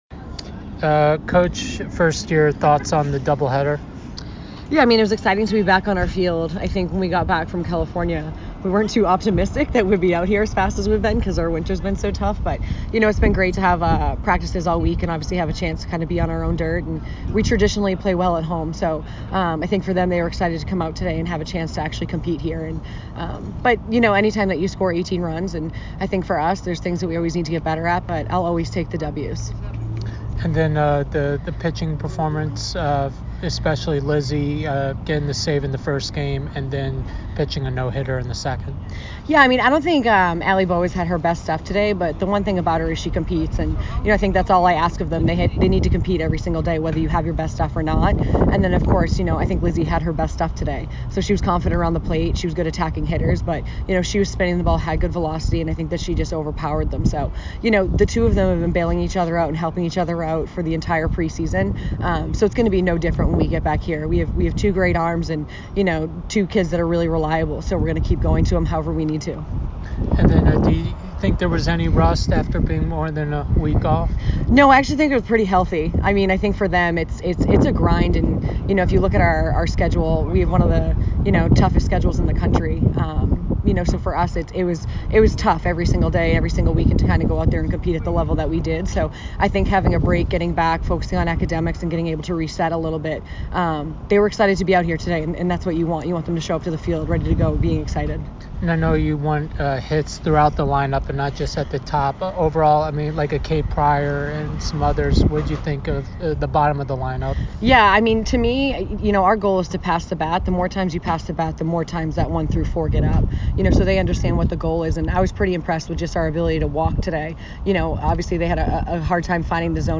Merrimack Softball Postgame Interview